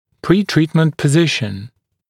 [ˌpriː’triːtmənt pə’zɪʃn][ˌпри:’три:тмэнт пэ’зишн]положение до начала лечения